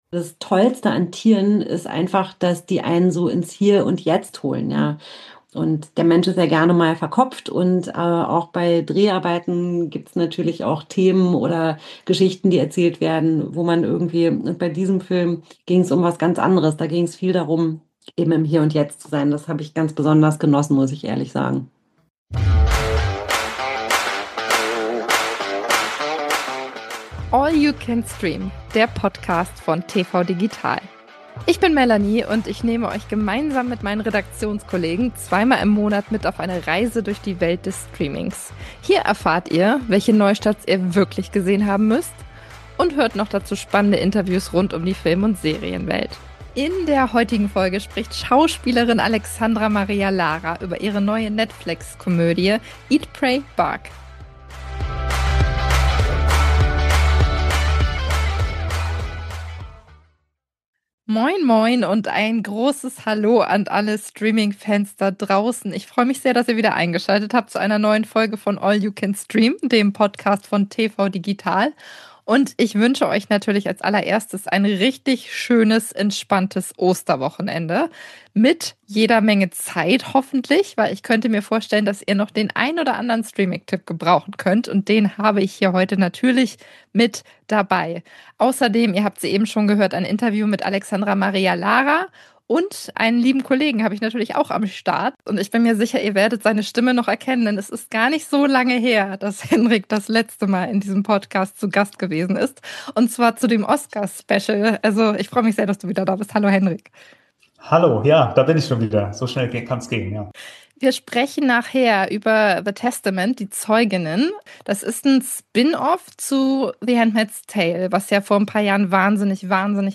Die größten Streaming-Highlights von Anfang bis Mitte April. UND: Exklusives Interview mit Schauspielerin Alexandra Maria Lara über ihre neue Netflix-Komödie "Eat Pray Bark".